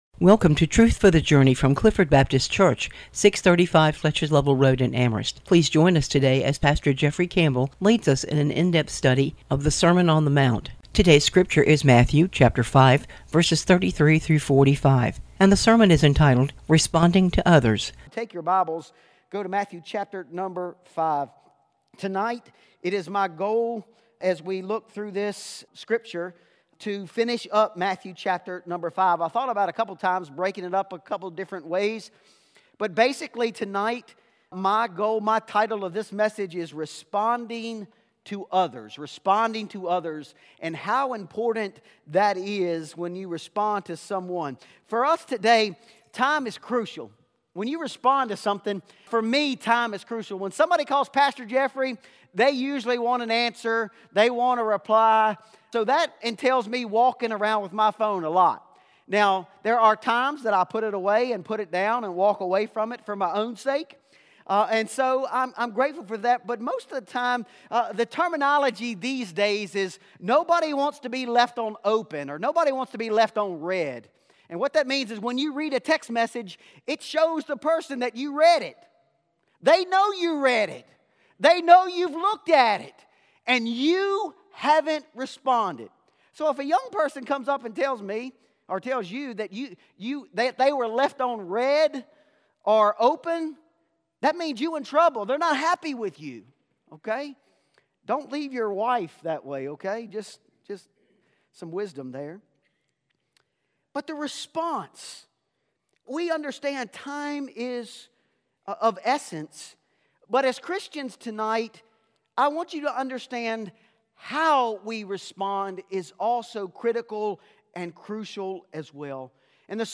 Sermon on the Mount, "Responding to Others", Matthew 5:33-45